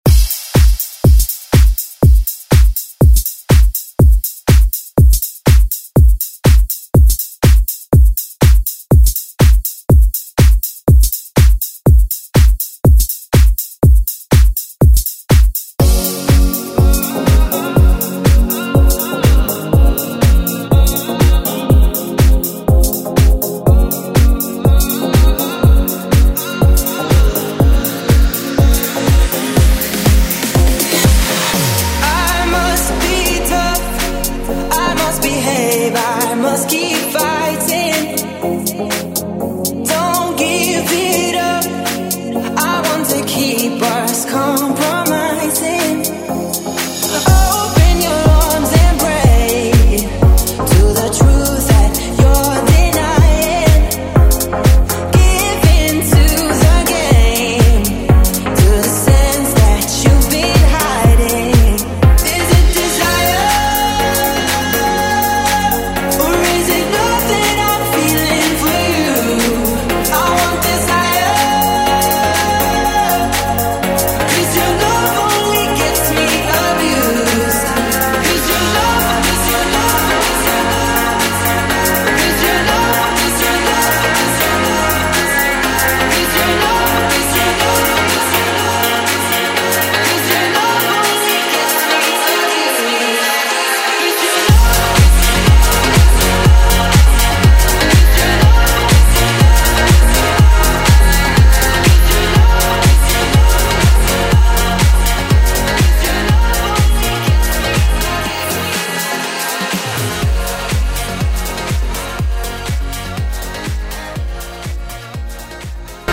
Electronic Hip Hop Pop Rap Music Extended Club ReWork
133 bpm
Genre: 90's